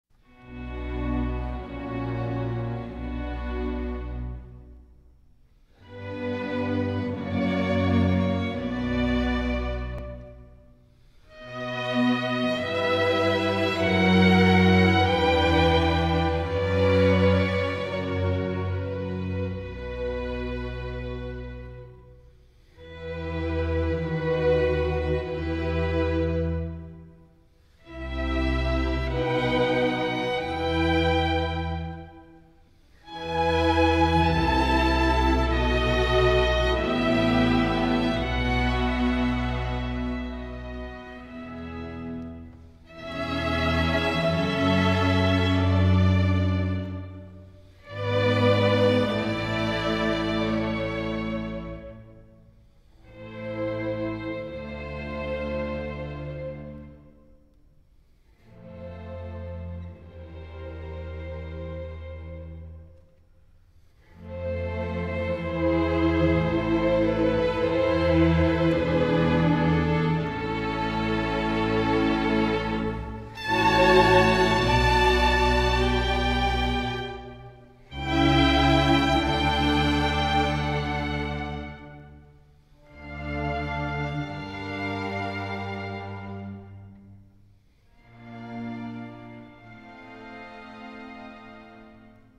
* Henry Purcell – Sonata in D for trumpet and strings: II. Adagio
youtube-sonata-in-d-for-trumpet-and-strings-ii-adagio_i98uirpc.mp3